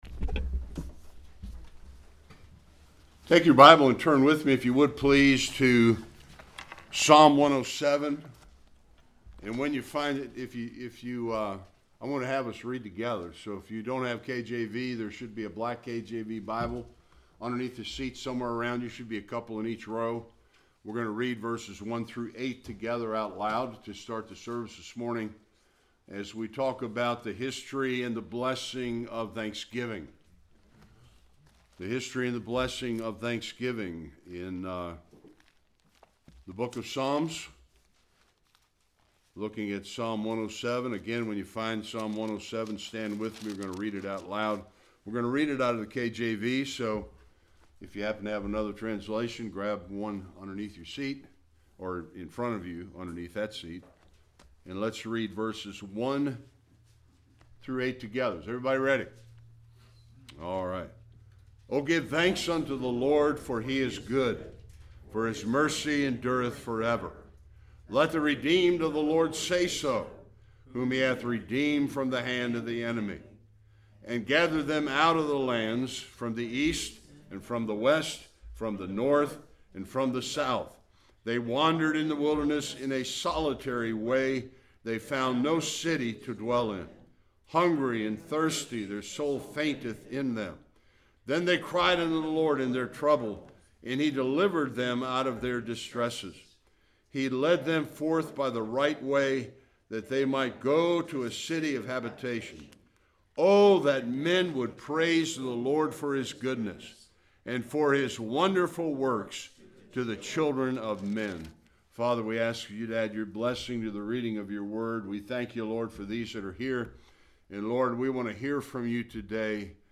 Various Passages Service Type: Sunday Worship What is the true history of Thanksgiving?